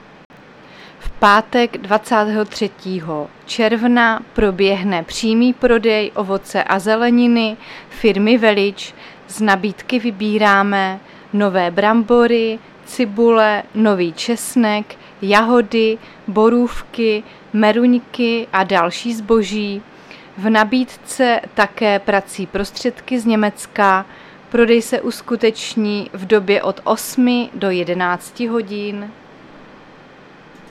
Záznam hlášení místního rozhlasu 21.6.2023